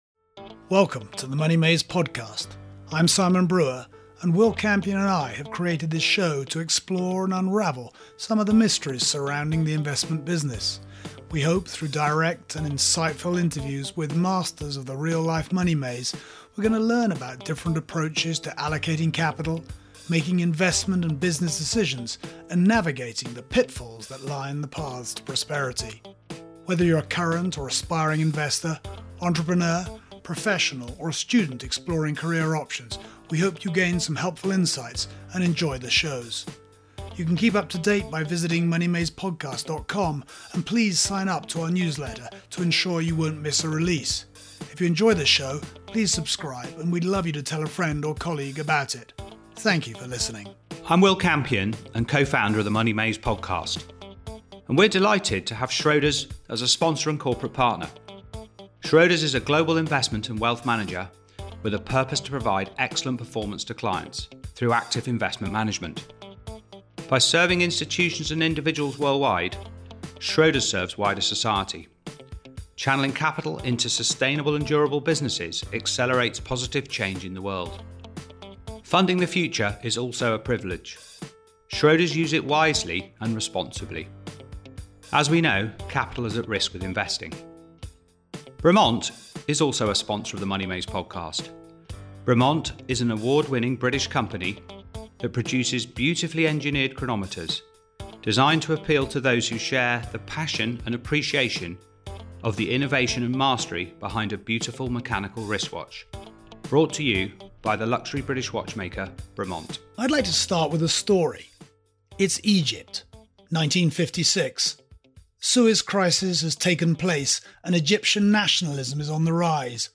Sir Ronald Cohen: From Venture Capital and Private Equity to Impact Investing: A Conversation with the Man Who Leads The Charge! [REPLAY]